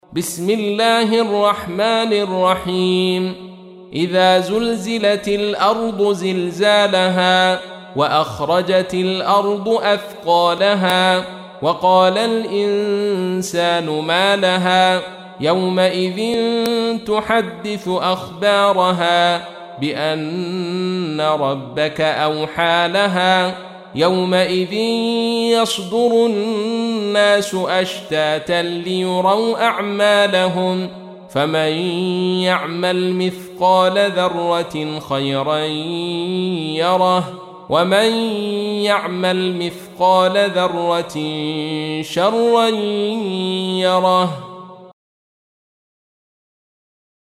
تحميل : 99. سورة الزلزلة / القارئ عبد الرشيد صوفي / القرآن الكريم / موقع يا حسين